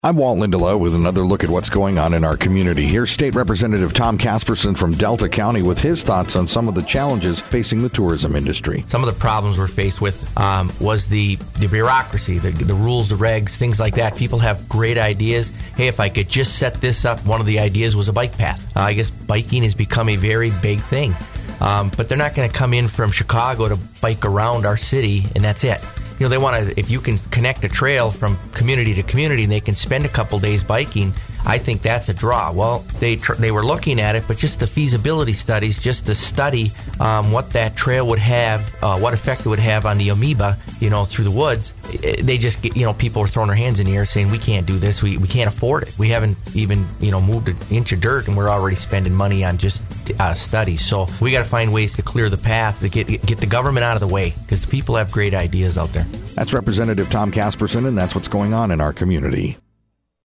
Discussion of a Task Force put together by Republican State Representative Tom Casperson from Delta County that studies tourism issues in the state.